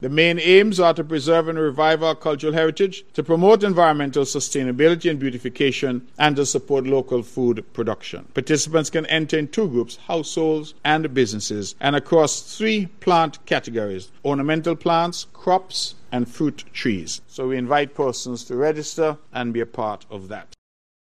During his monthly press conference, Premier and Minister of Tourism, the Hon. Mark Brantley shared the aims: